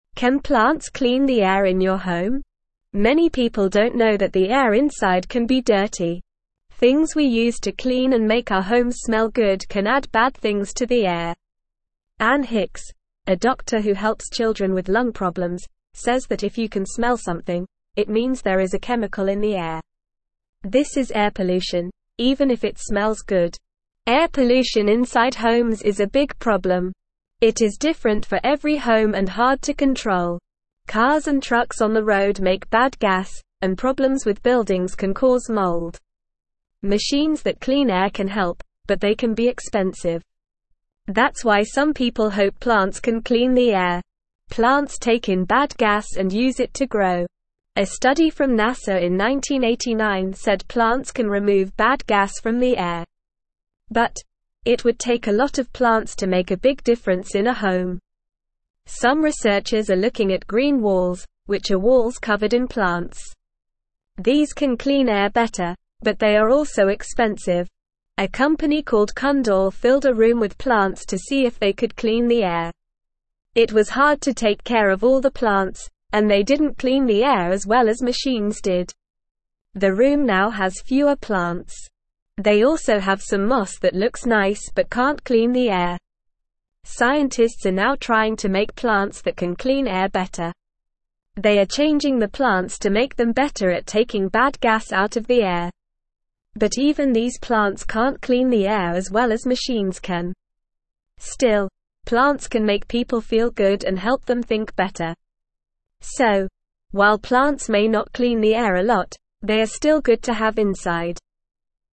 Normal
English-Newsroom-Beginner-NORMAL-Reading-Can-Plants-Clean-Our-Home-Air.mp3